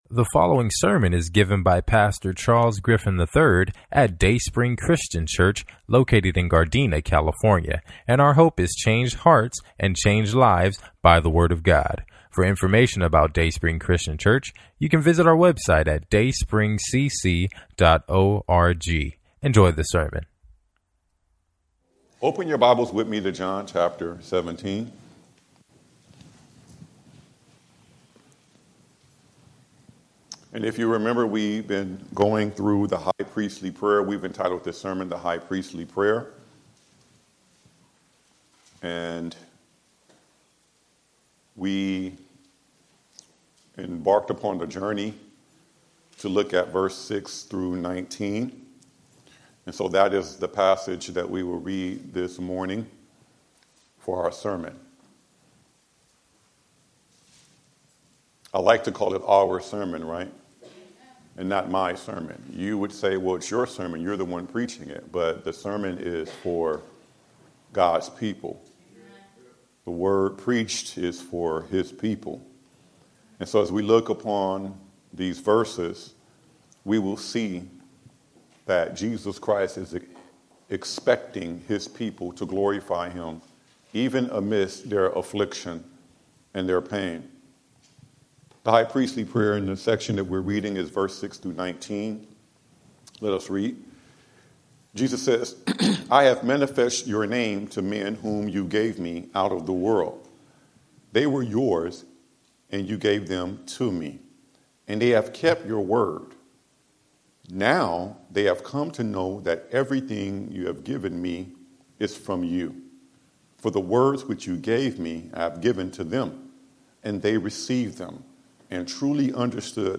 Sermon Series: John 17 – The High Priestly Prayer